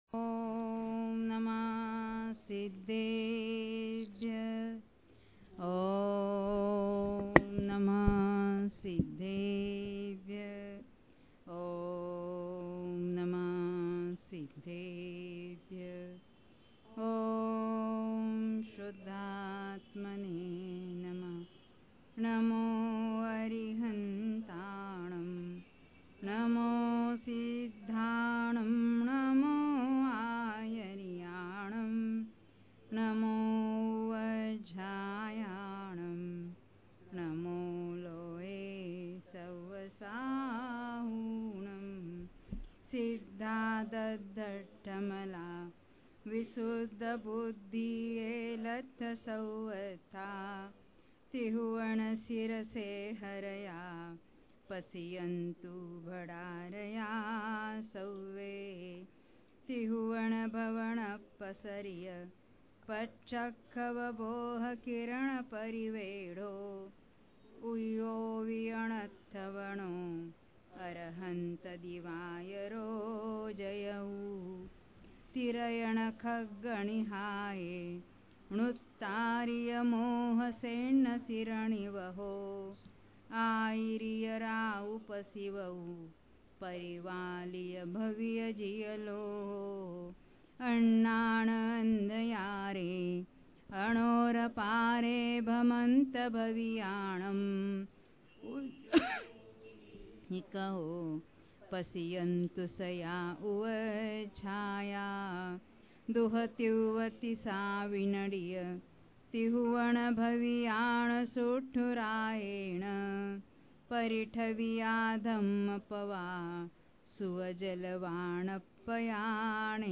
Pravachan